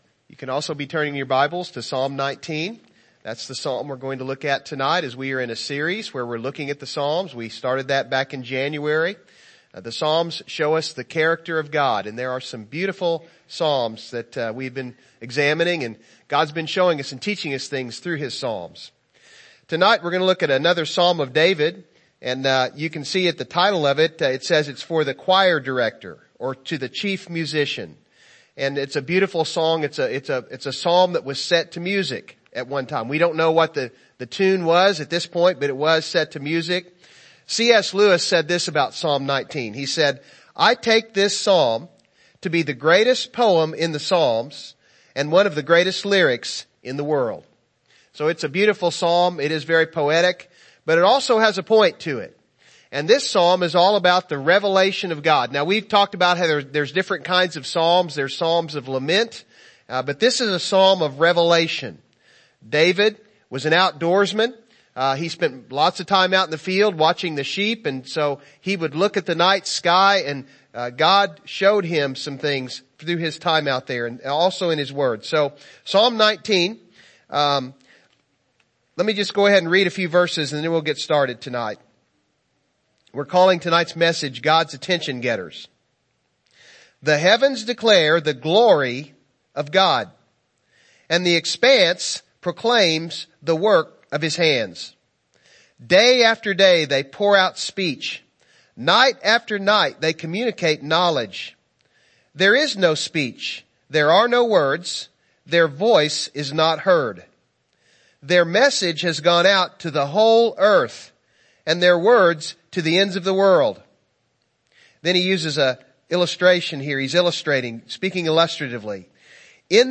The Character of God Service Type: Evening Service « In the End